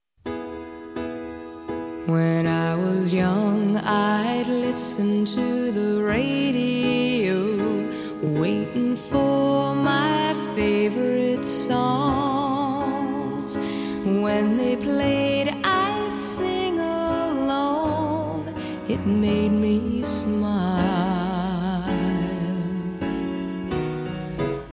常常略读，舌头和口型到位，但是不发音。
3.音素/t/在非重读的/n/之前时，也常常略读，舌头和口型到位，但直接发/n/的音。